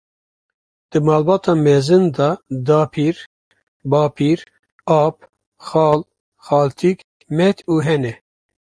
Uitgesproken als (IPA)
/xɑːl/